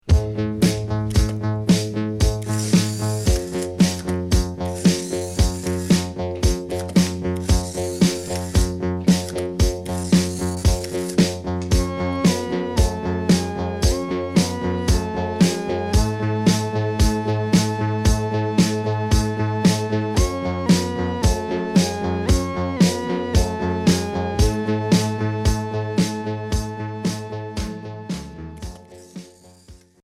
Minimal synth